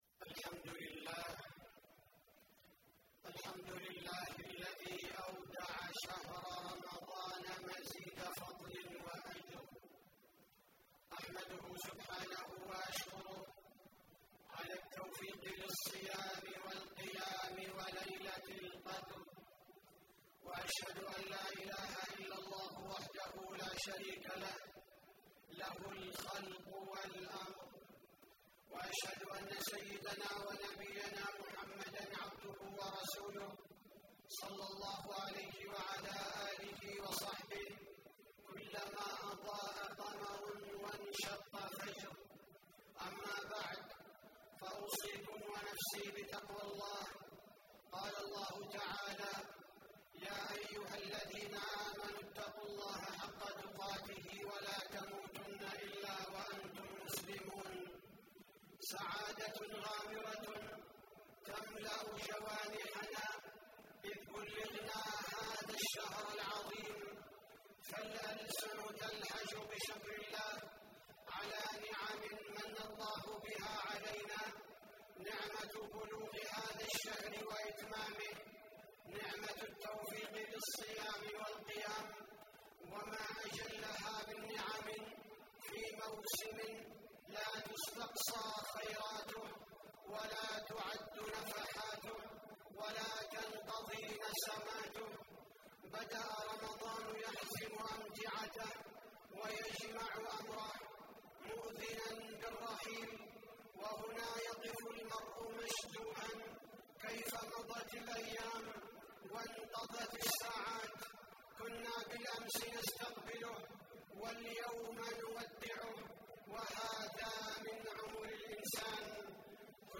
تاريخ النشر ٢٨ رمضان ١٤٣٨ هـ المكان: المسجد النبوي الشيخ: فضيلة الشيخ عبدالباري الثبيتي فضيلة الشيخ عبدالباري الثبيتي رمضان دروس وعبر